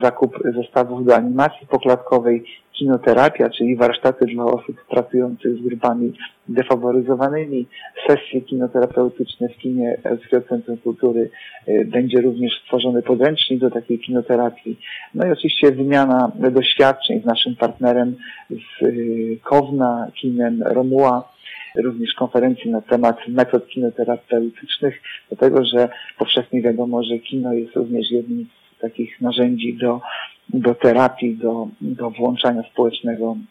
W lipcu placówka, we współpracy z kinem Romuva z Kowna, chce wystartować z projektem „Zamknięci w celuloidzie – film jako narzędzie włączenia społecznego”. Jak mówi Artur Urbański, zastępca prezydenta Ełku, w planach jest szereg działań, których wspólnym mianownikiem będzie terapia poprzez pracę z filmem.